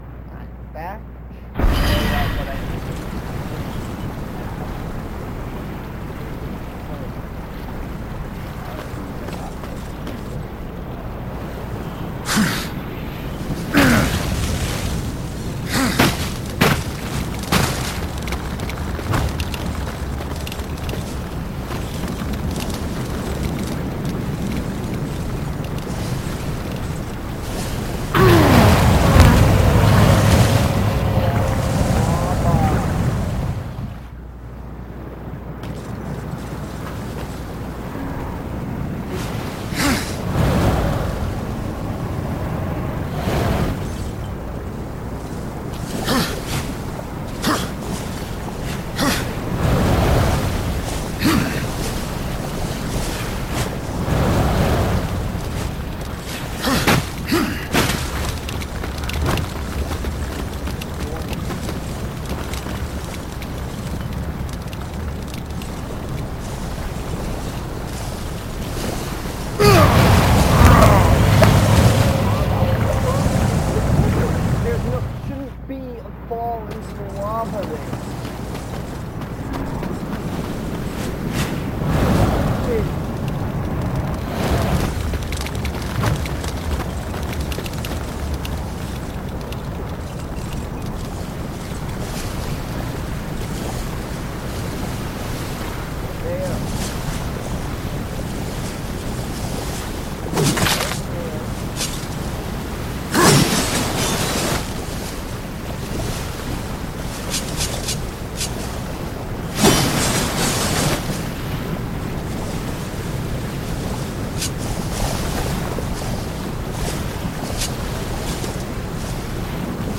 I play Darksiders with commentary